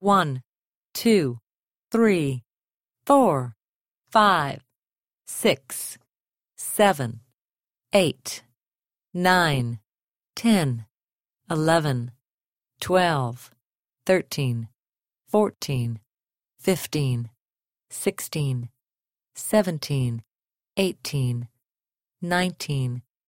Звуки чисел
1-19 (женский голос, слегка уникальный)